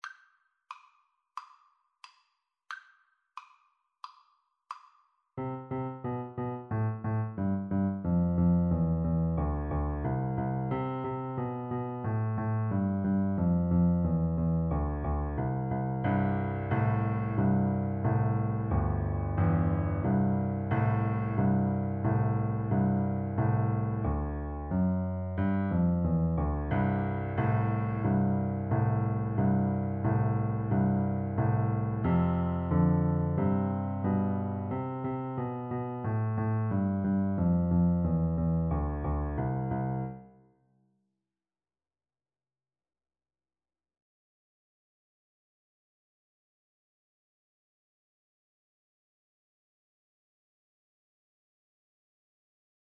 A beginners piece with a rock-like descending bass line.
March-like = 90
Pop (View more Pop Flute Music)